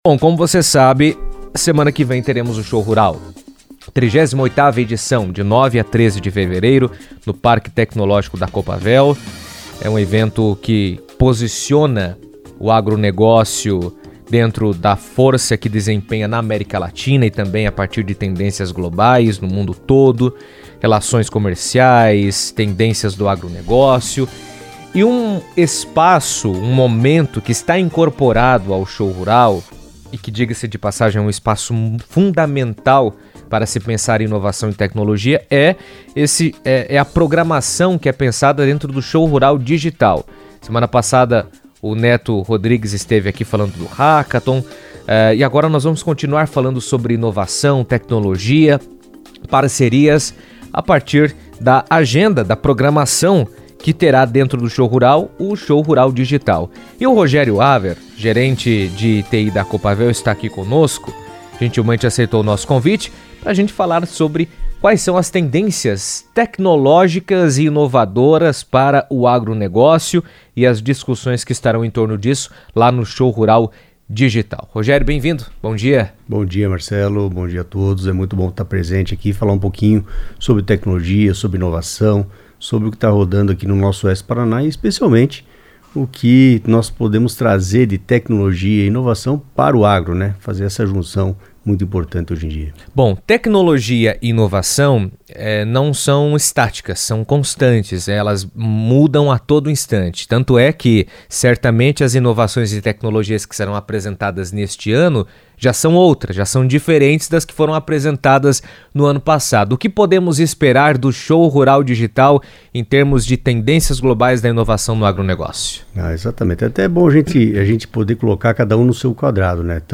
comentou em entrevista à CBN que a integração da tecnologia no campo será fundamental para aumentar eficiência